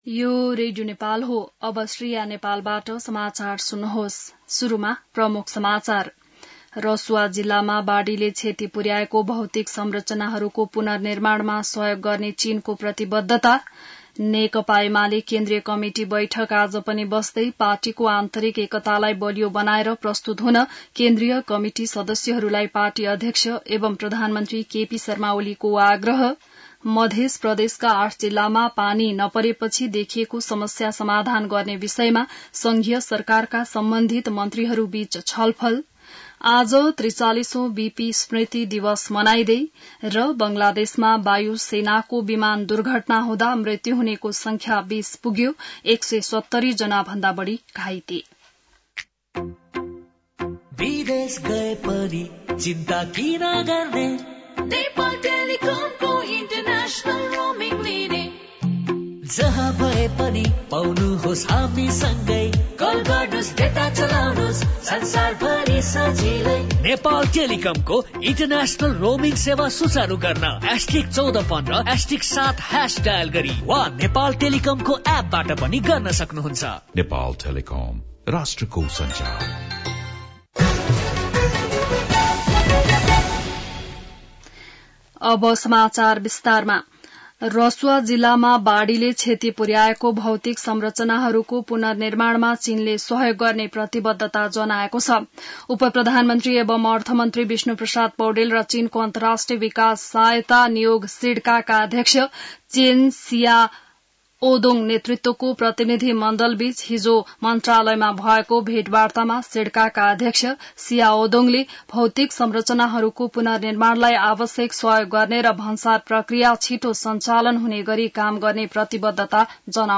An online outlet of Nepal's national radio broadcaster
बिहान ७ बजेको नेपाली समाचार : ६ साउन , २०८२